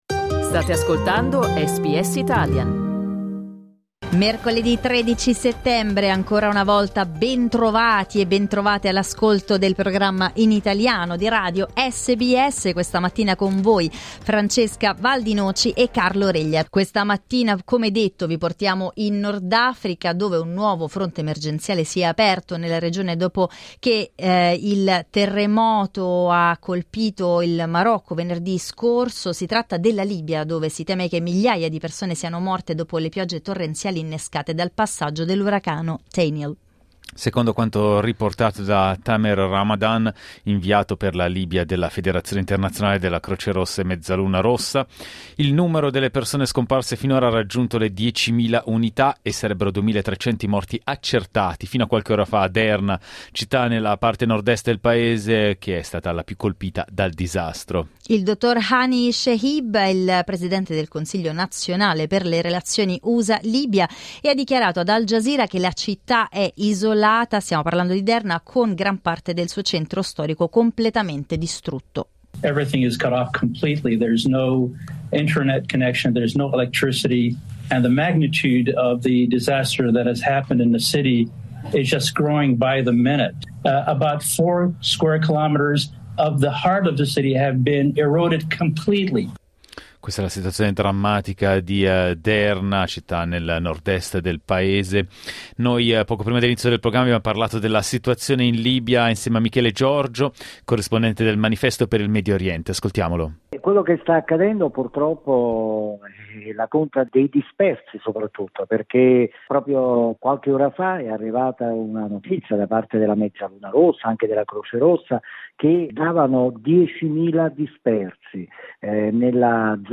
il servizio del corrispondente